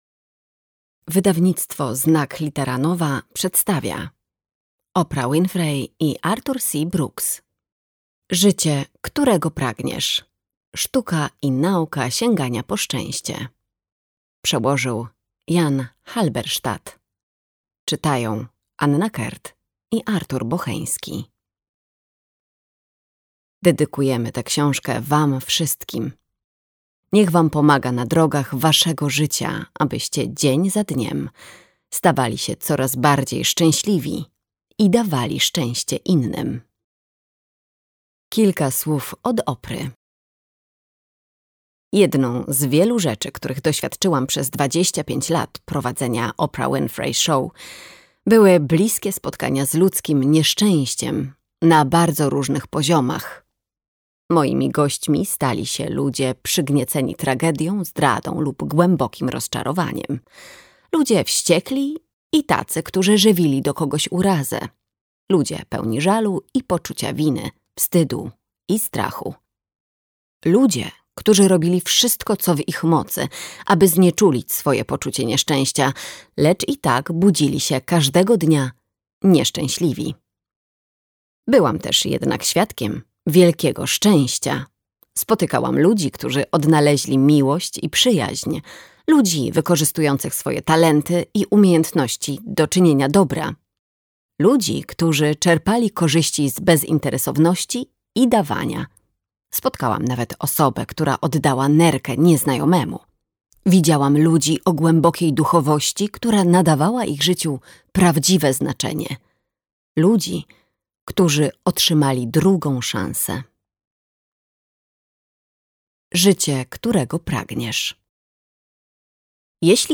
Sztuka i nauka sięgania po szczęście - Oprah Winfrey, Arthur C. Brooks - audiobook